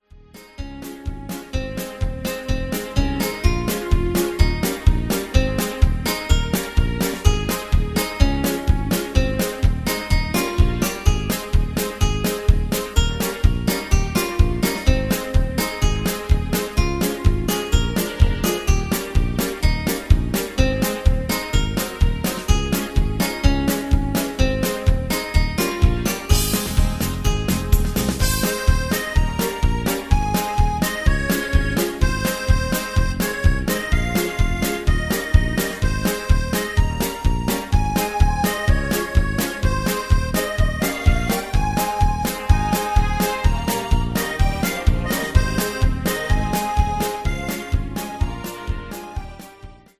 Category: Patter